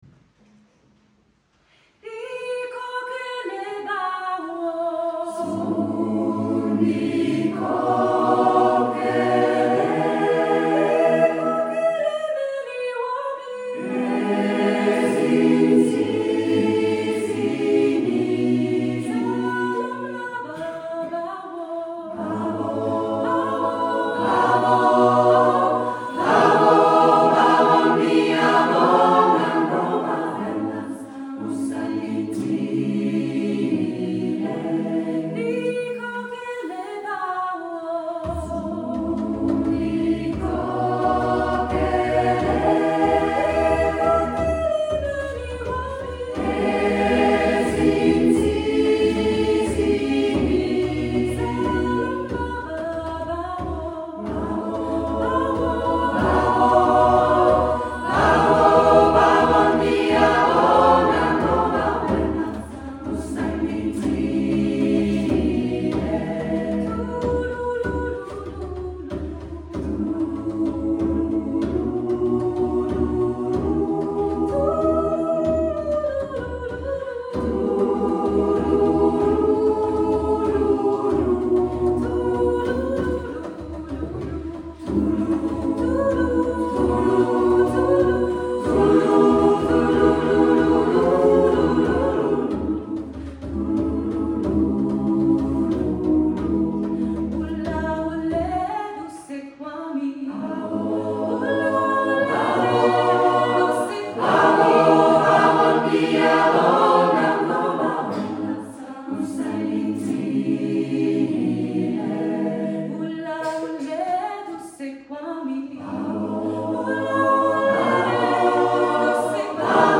Chormusik
Wir sind eine geschlechts- und altersdurchmischte Gruppe, teils mit, teils ohne bisherige Chorerfahrung.
Wir singen a cappella Volkslieder aus der Schweiz und aus anderen Ländern, Pop, Klassisches... und dies auf einem guten musikalischen Niveau.
• Mehr als 20 Musikanten / Sänger